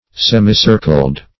Semicircled \Sem"i*cir`cled\, a.